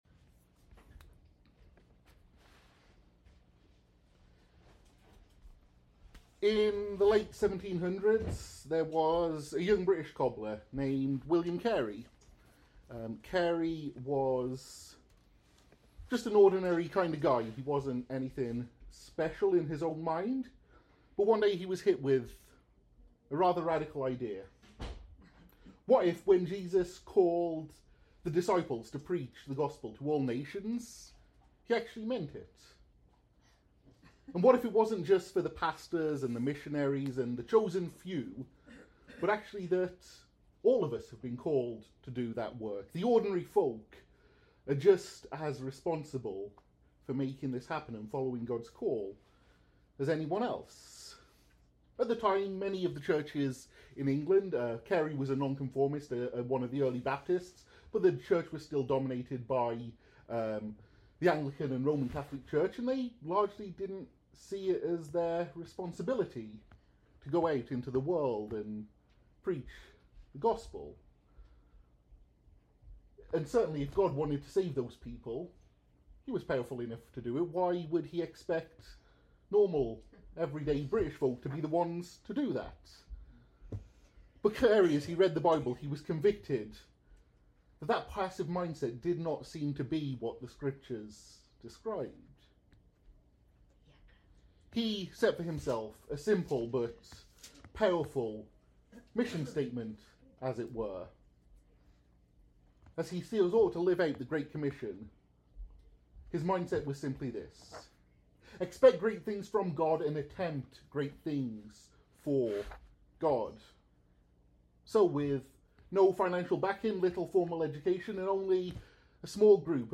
Join Us for Our 1-Year Anniversary Service!
In this special sermon, we’ll reflect on Acts 2:42-47, seeing how the early church grew through devotion to God’s Word, deep fellowship, prayer, and awe at His presence—the same foundations that have shaped our church in the past year.